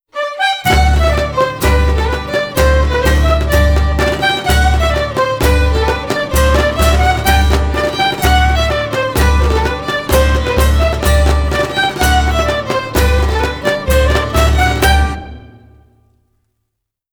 24 BALL D'AUTORITATS DAMES I VELLS Músics del Ball de Dames i Vells
Santa Tecla Tarragona